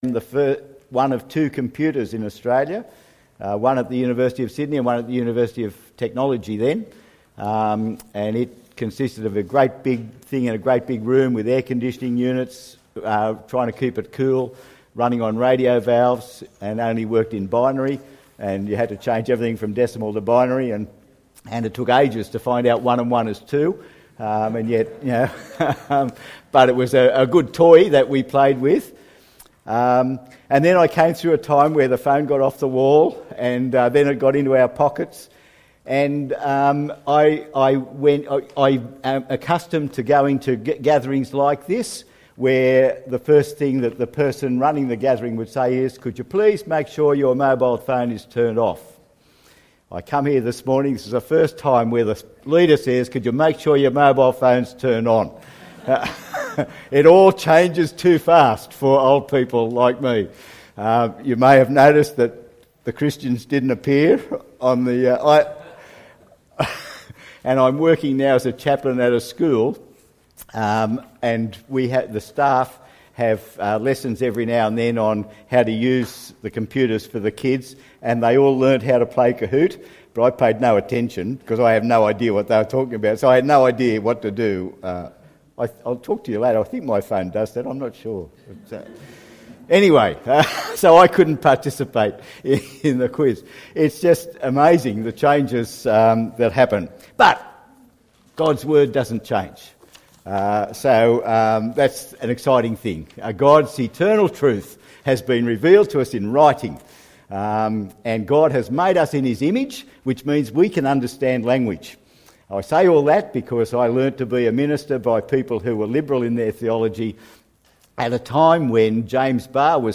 The Work of Faith Preacher